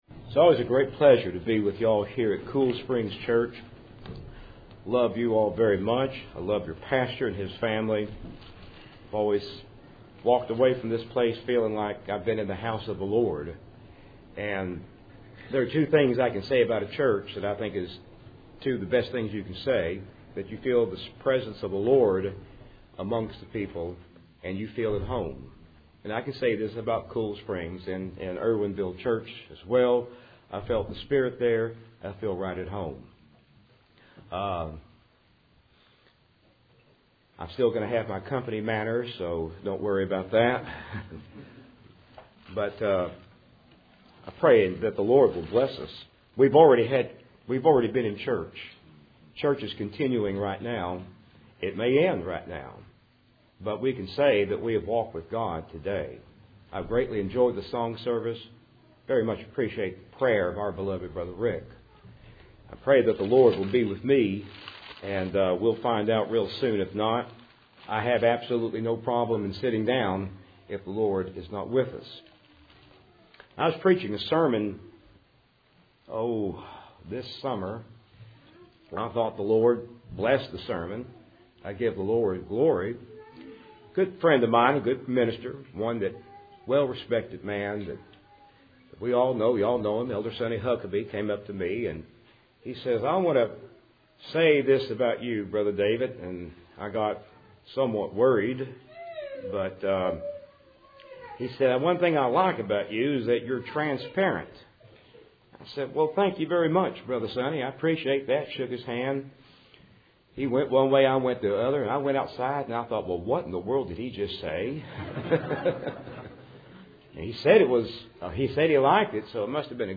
Service Type: Cool Springs PBC Sunday Evening